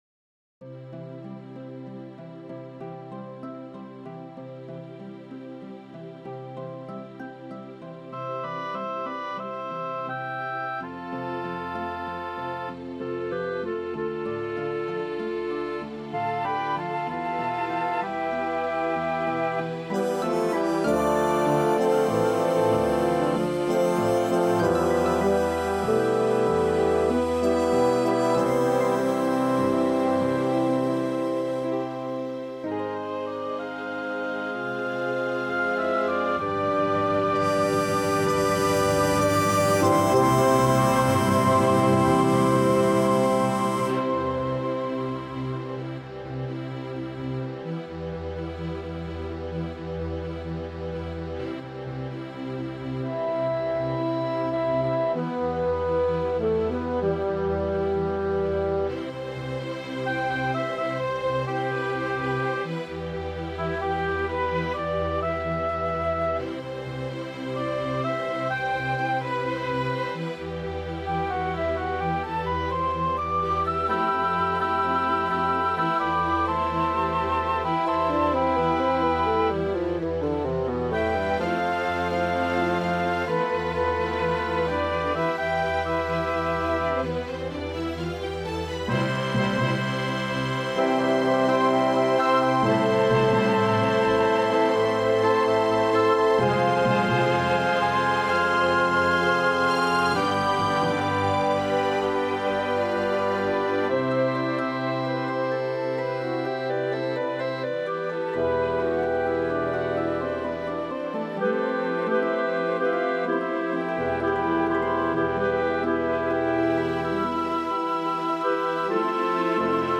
Orchestration, Arrangement and Additional Music by
A nice piece that began its life as a song for voice and guitar and then subsequently, was arranged for full symphony orchestra, and then later revised. Replete with counter-melodies and harmonies this composition is a good example of how a simpler setting can be expanded to fulfill the potential of a full orchestra. The vocal part is always doubled in the instruments so the work stands as well with the voice (as intended) or without (as in the virtual realization of the score).